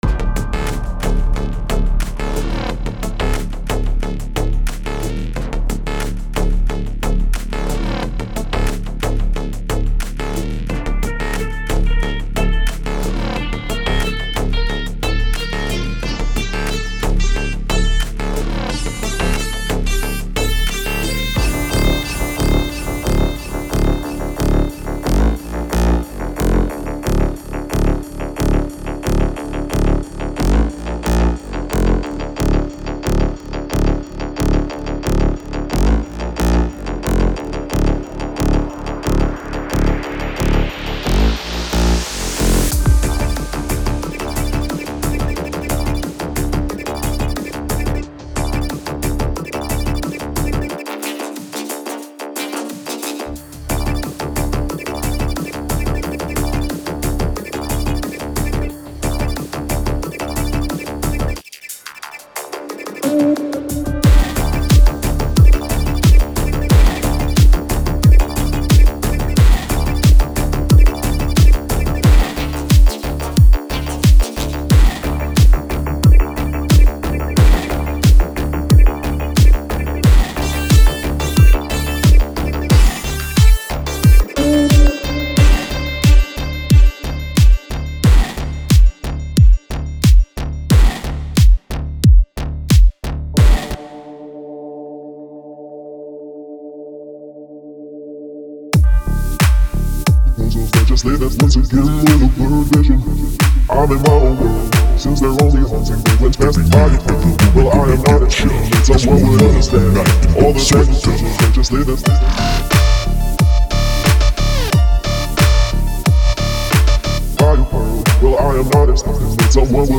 Genres : EDM, Electro